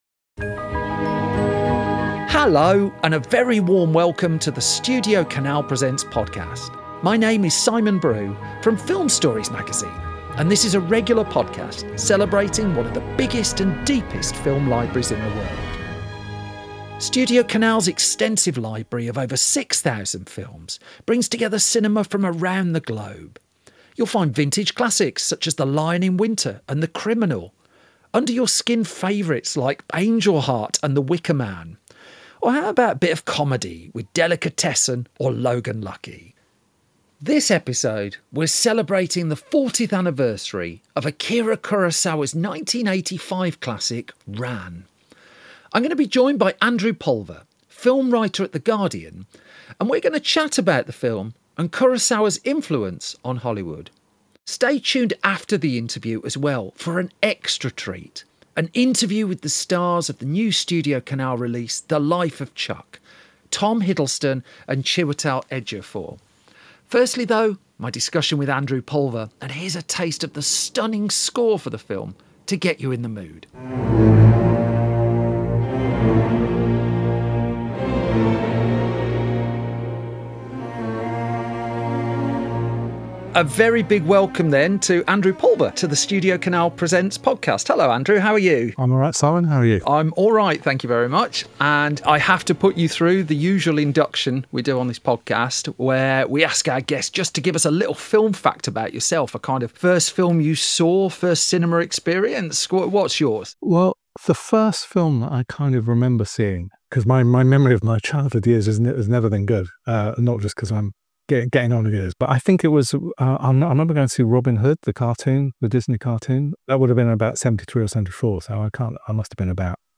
In come Chiwetel Ejiofor and Tom Hiddleston, two of the stars of Mike Flanagan's The Life Of Chuck , which heads to UK cinemas on 20th August.